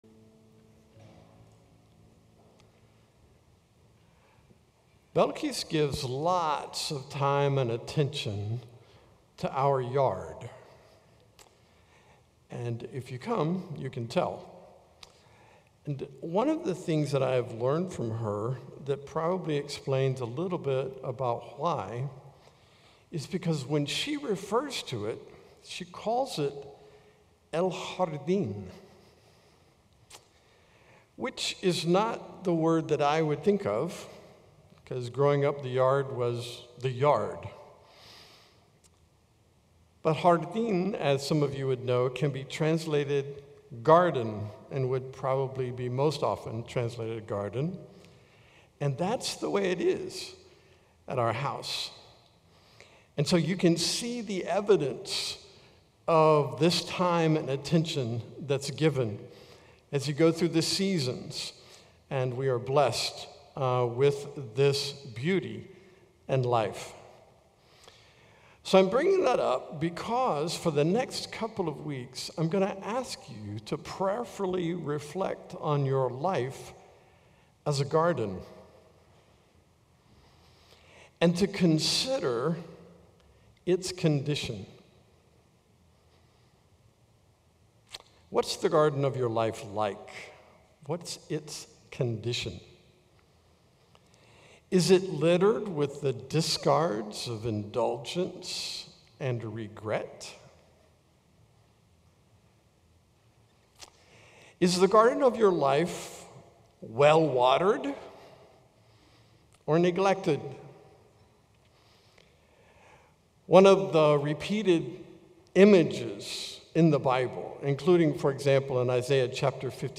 Sermons - Calvary El Calvario